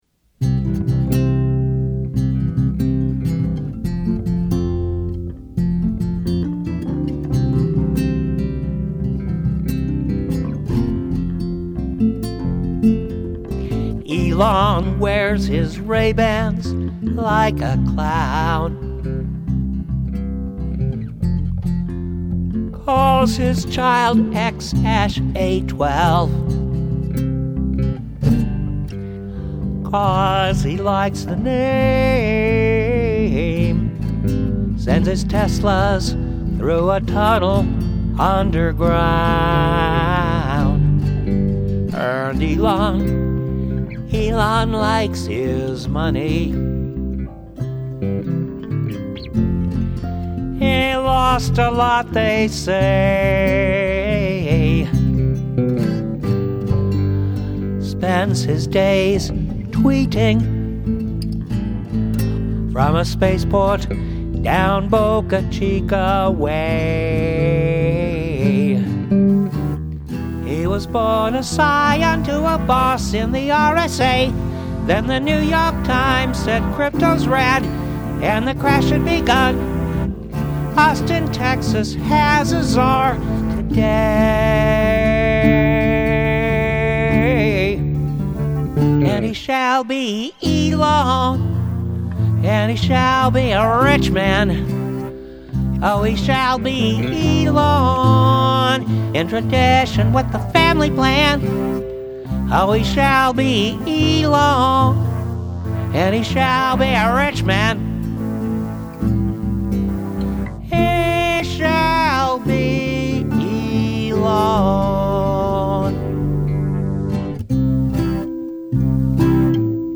electric
lead guitar